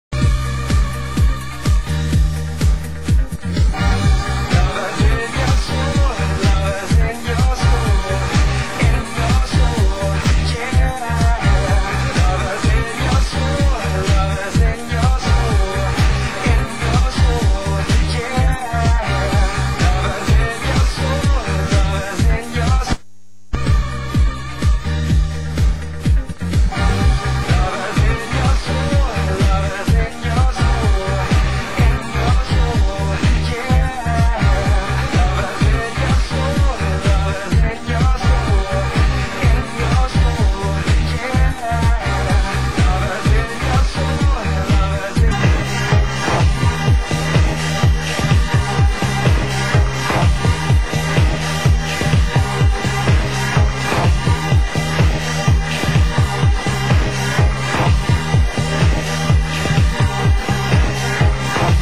Genre: French House